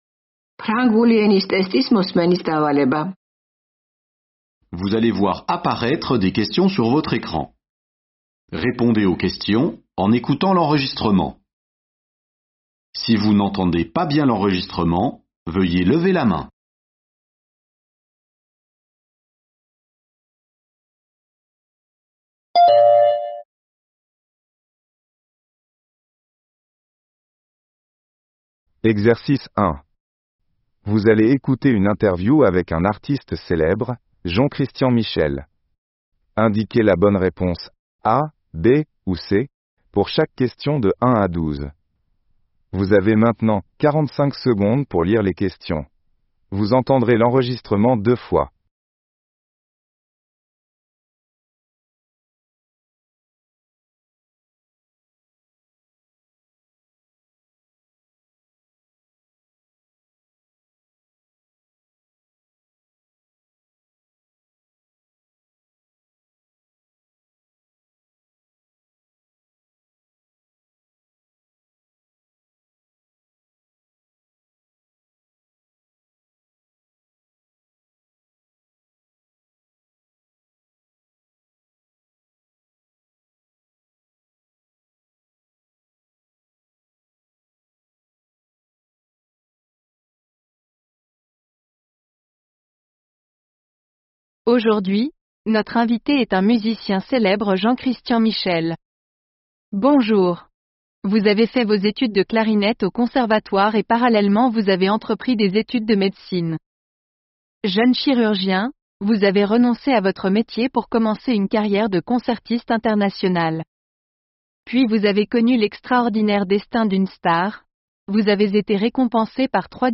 ფრანგული ენა მოსმენის დავალება